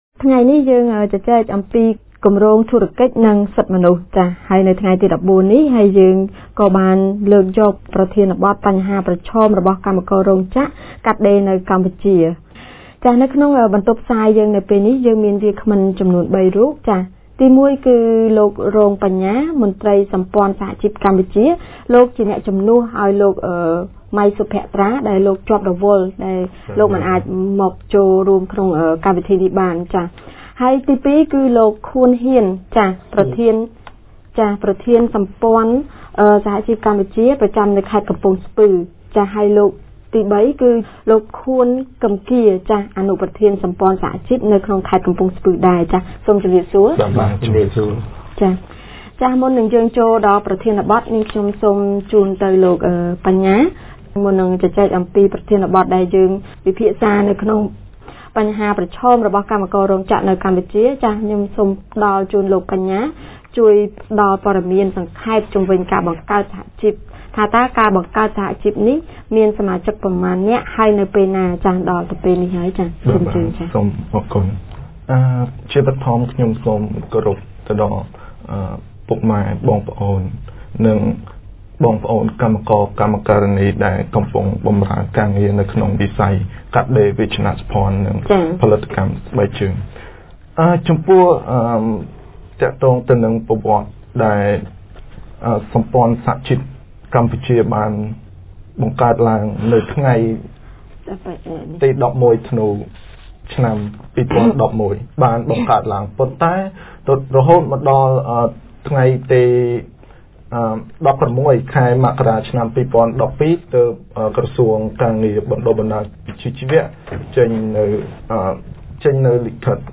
On 24 December 2013, Business and Human Rights Project conducted a radio talk show discussing on the key challenges of garment workers in Cambodia. Three labor unions from Cambodian Alliance of Trade Union highlight the issues of garment workers including poor working conditions, low wages, and other related labor rights abuses within and outside workplaces.